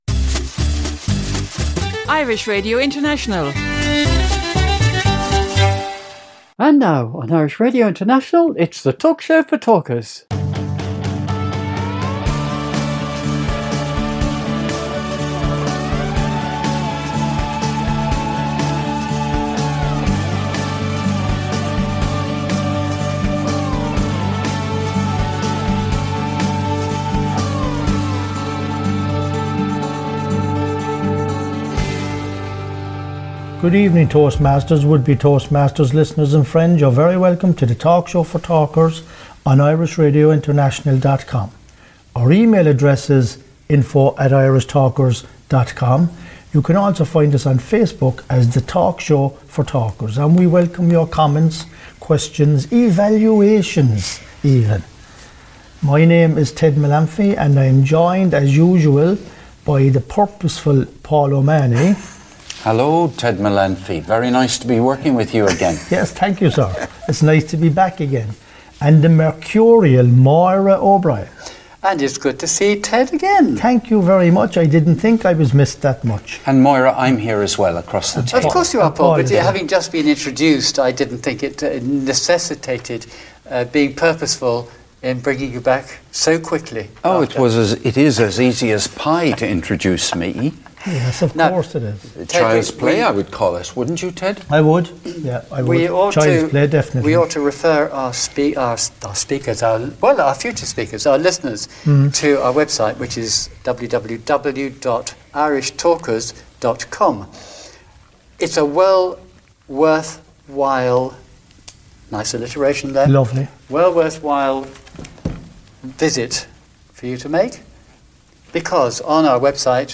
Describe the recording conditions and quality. This 50 minute episode of 'The Talk Show for Talkers' comes from Cork Ireland - first broadcast on Irish Radio International.